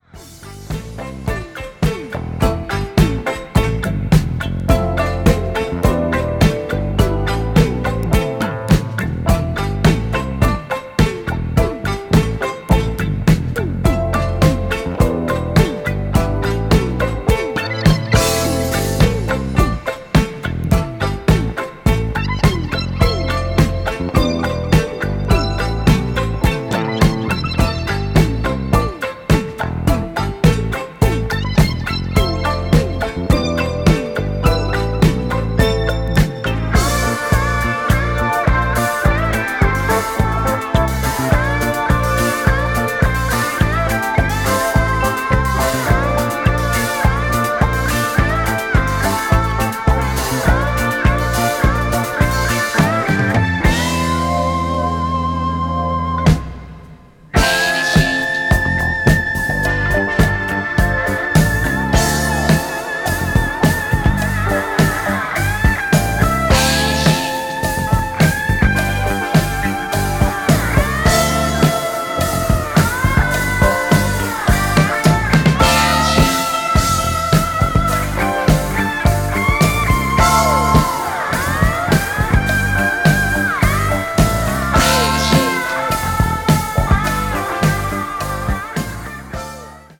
東京の真夜中に聴くサウンドトラック的なテーマでコンパイルされた日本人アーティストの楽曲のみを収録したコンピレーション